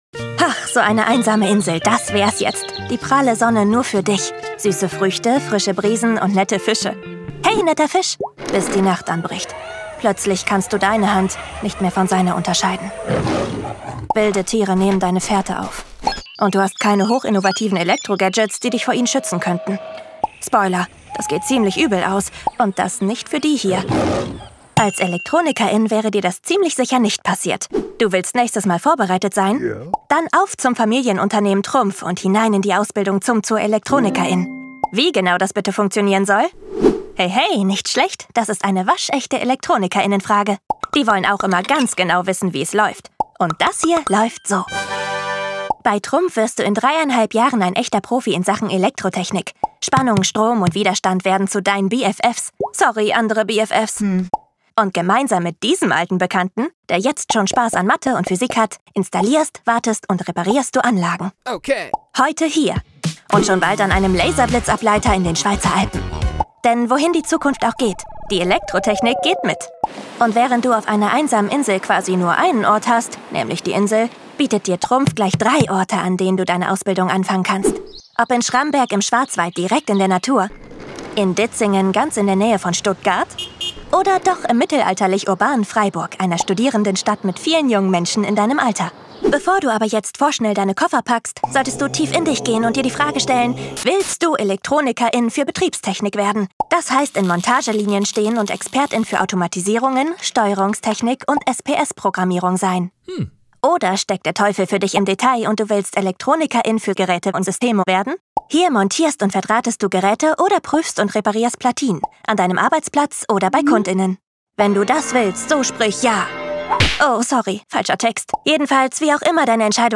plakativ, sehr variabel
Jung (18-30)
Station Voice